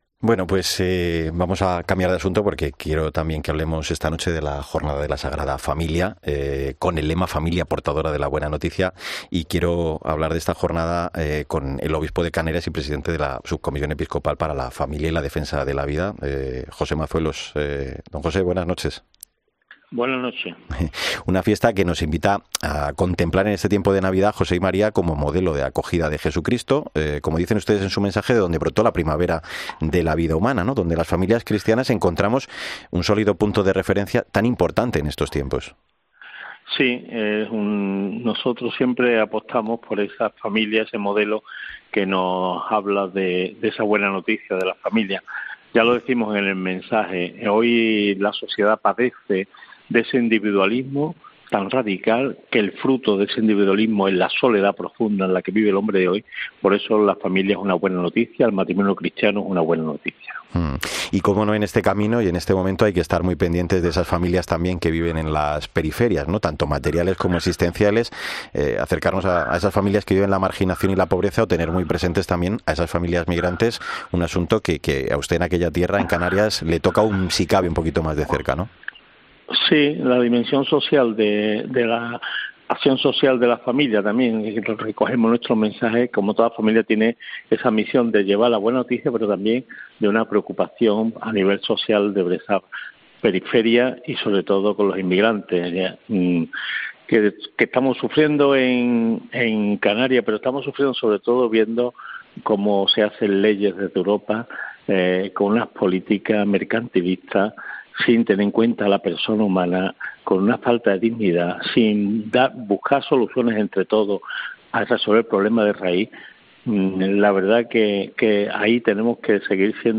Entrevista de José Mazuelos en 'La Linterna de la Iglesia'